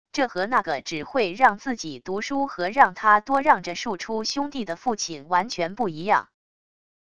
这和那个只会让自己读书和让他多让着庶出兄弟的父亲完全不一样wav音频生成系统WAV Audio Player